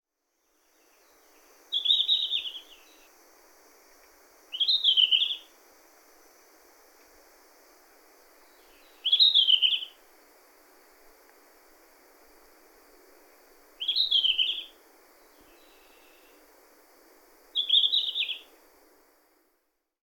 １　ルリビタキ【瑠璃鶲】　全長約14cm
【録音2】 　2025年8月5日　日光白根山
優しい感じのさえずりを続ける個体の声